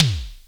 Index of /90_sSampleCDs/Roland L-CD701/TOM_E.Toms 1/TOM_Analog Toms1
TOM MED TO08.wav